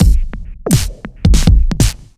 Index of /musicradar/off-the-grid-samples/110bpm
OTG_Kit10_Wonk_110b.wav